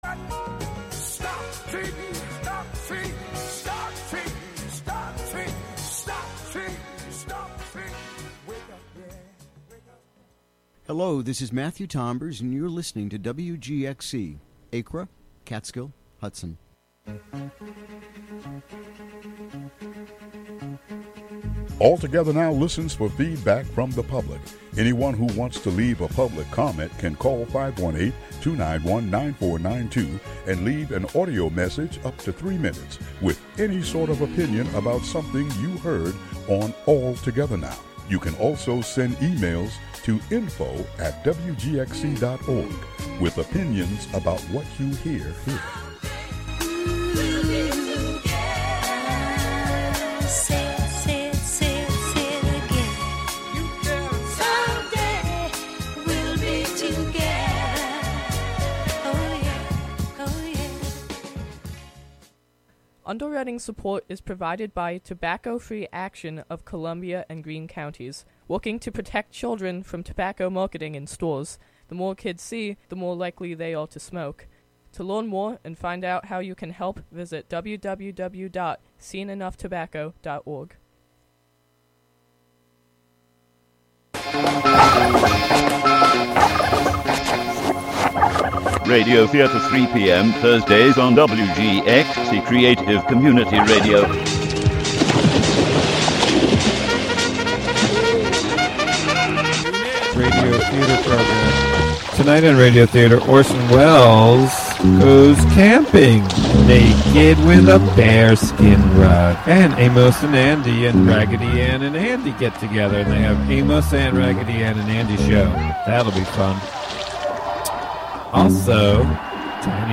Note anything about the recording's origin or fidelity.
monthly broadcast featuring comedy, music and talk